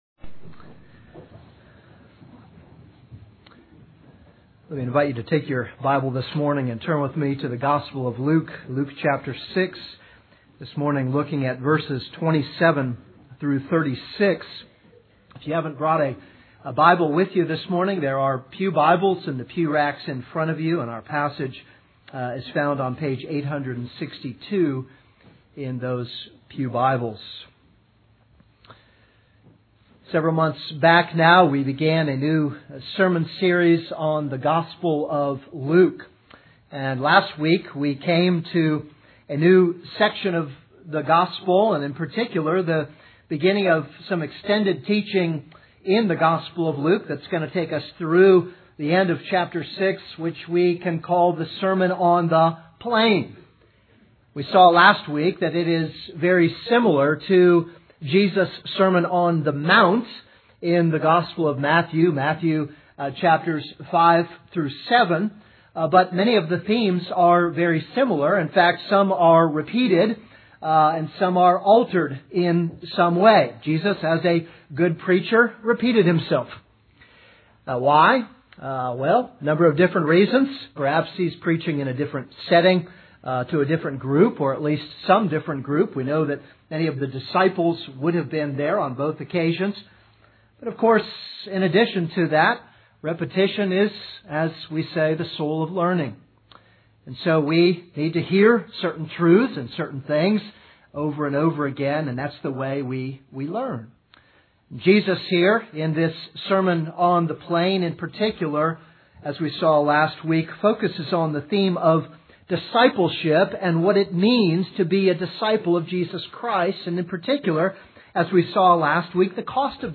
This is a sermon on Luke 6:27-36.